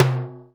RS TIMB L.wav